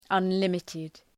Προφορά
{ʌn’lımıtıd} (Επίθετο) ● απεριόριστος